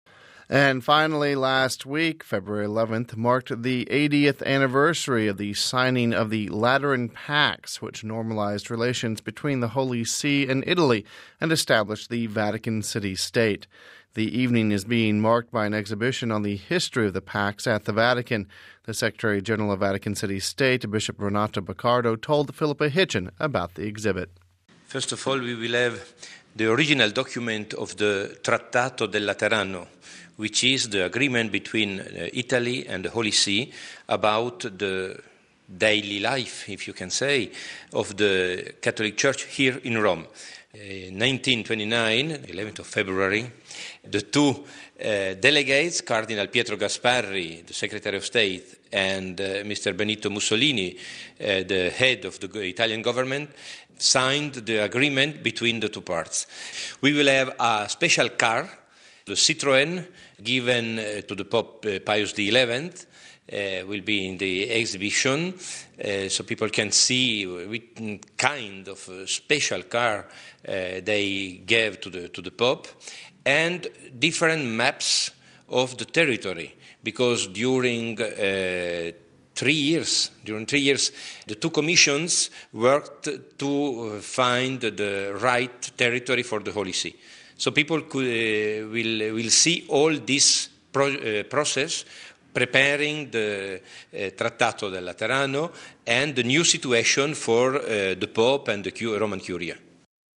The event is being marked by an exhibition on the history of the Pacts at the Vatican. The secretary-general of Vatican City State, Bishop Renato Boccardo, told us about the exhibit...